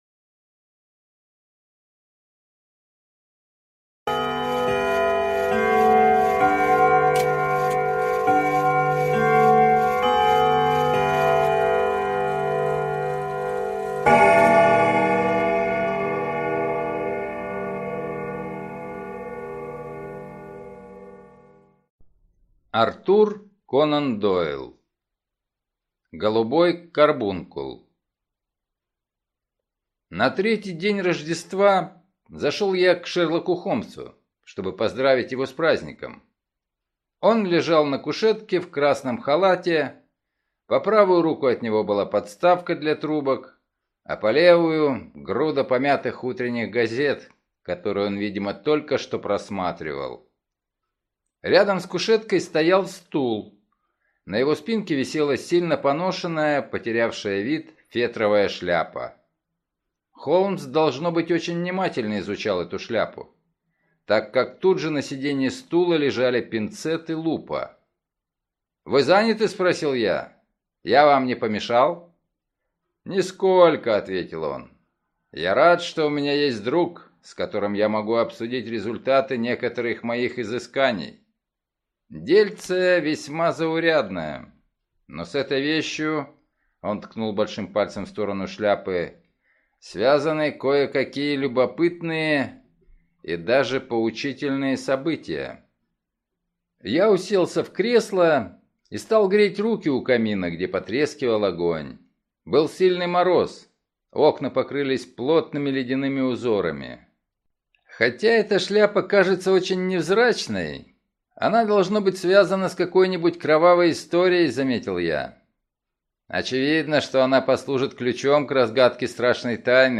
Голубой карбункул - аудио рассказ Конана Дойла. Загадочное исчезновение редкого голубого карбункула приводит Шерлока Холмса к расследованию в фешенебельном отеле, где драгоценность была похищена из апартаментов знатной графини.